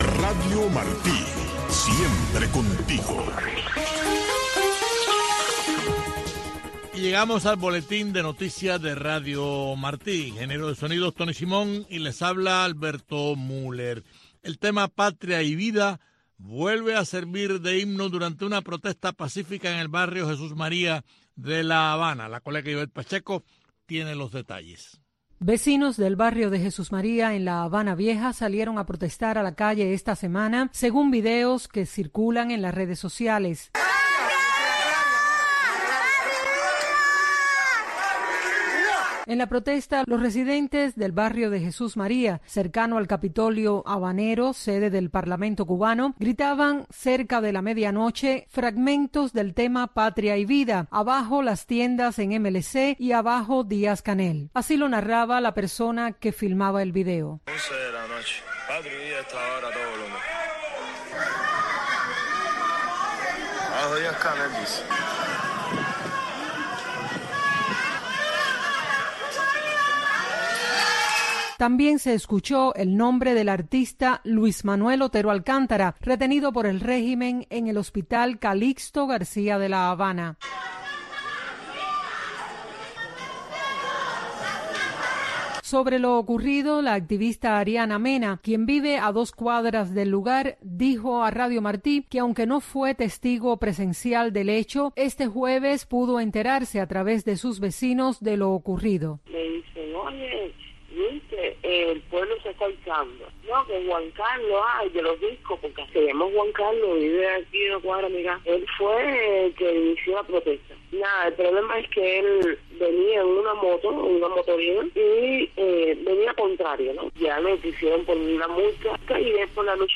Entrevistas e informaciones con las voces de los protagonistas desde Cuba. Servirá de enlace para el cubano conozca lo que sucede en el país sin censura.